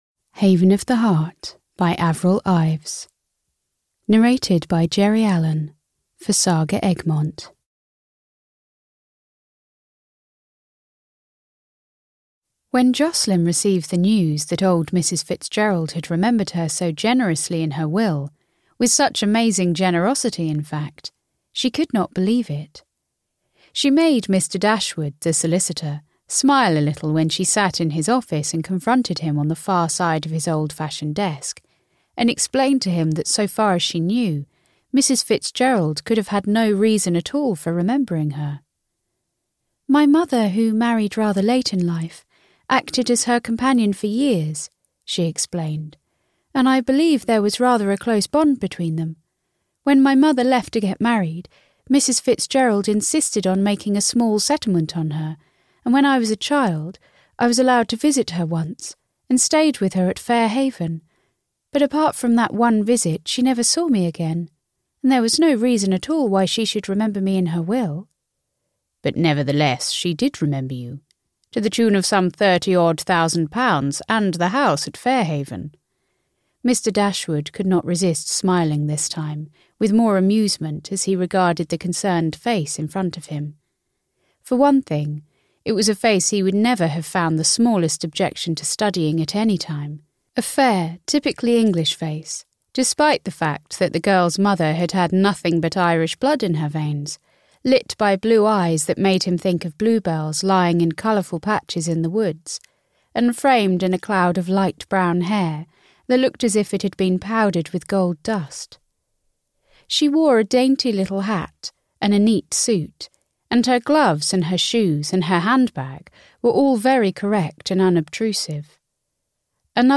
Audio knihaHaven of the Heart (EN)
Ukázka z knihy